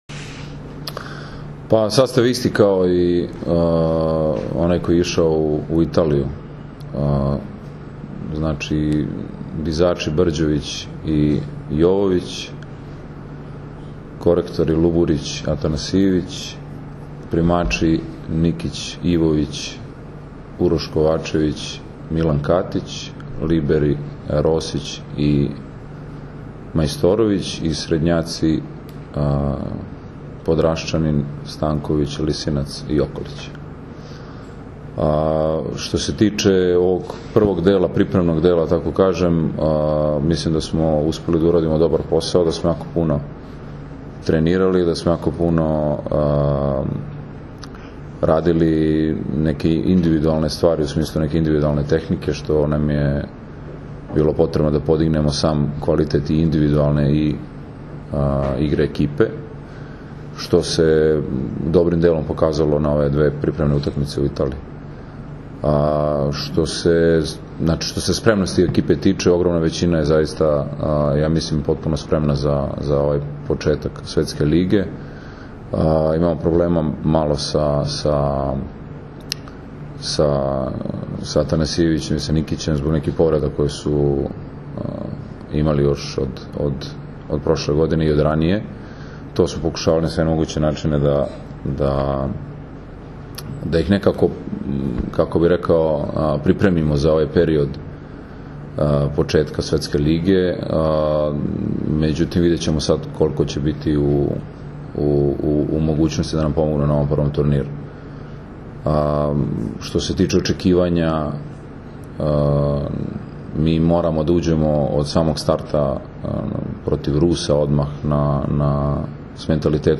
Tim povodom, danas je u beogradskom hotelu “M” održana konferencija za novinare, na kojoj su se predstavnicima medija obratili Nikola Grbić, Dragan Stanković, Aleksandar Atanasijević i Miloš Nikić.
IZJAVA NIKOLE GRBIĆA